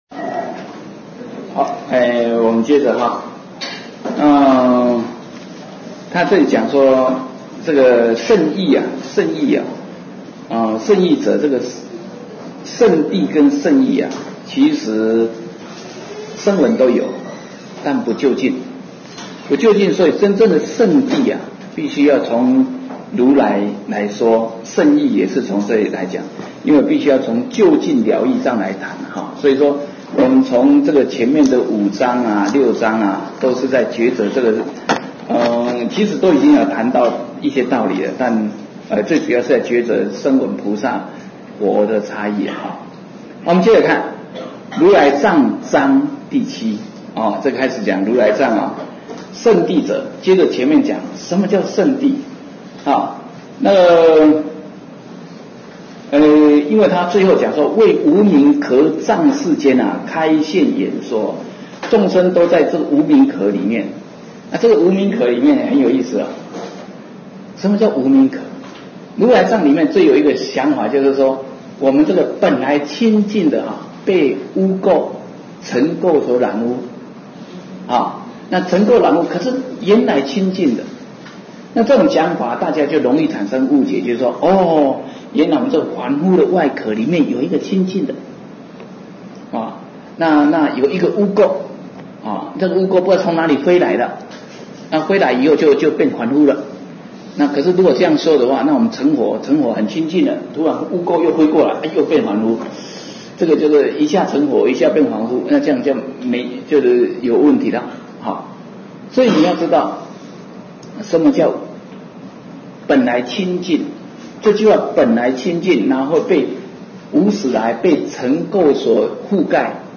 41胜鬘经(玉佛寺)